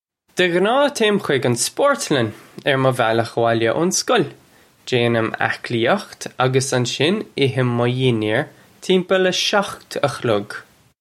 Pronunciation for how to say
Guh ghnaw, tay-im hig un sport-lun urr muh val-ukh uh-wolya own skol. Jaynim ak-lee-ukht uggus un-shin ihim muh yin-yare chim-pull uh shokht uh khlug.
This is an approximate phonetic pronunciation of the phrase.